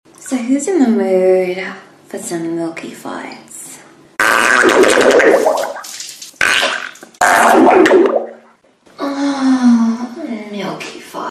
Milky Farts Sound Effect Download: Instant Soundboard Button